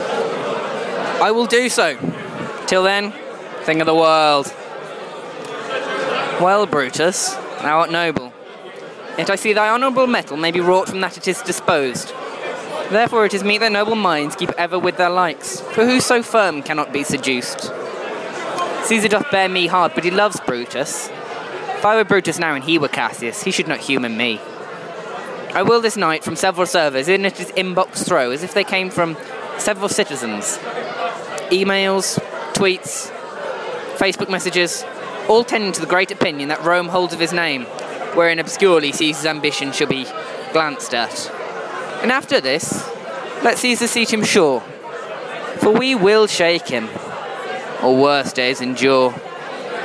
reads Cassius' soliloquy from Act I, Scene II of Shakespeare's Julius Caesar (with a few contemporary edits).